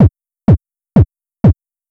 KICK013_DISCO_125_X_SC3.wav